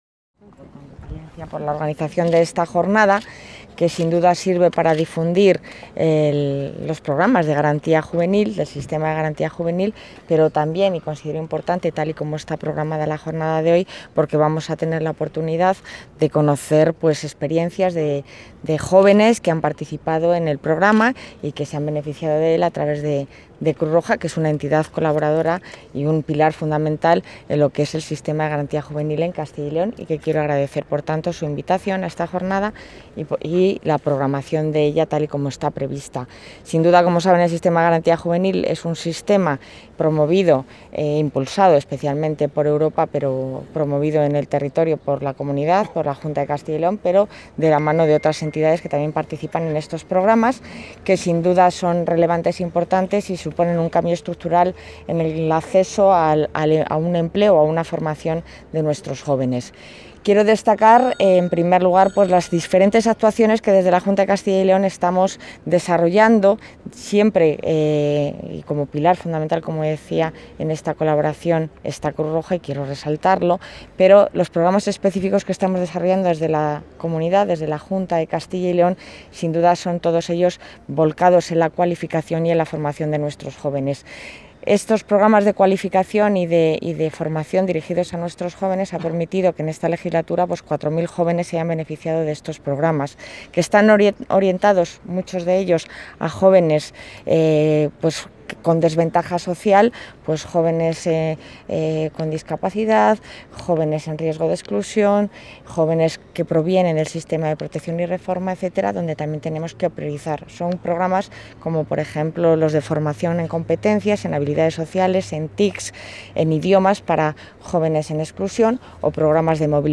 Declaraciones de la consejera de Familia e Igualdad de Oportunidades.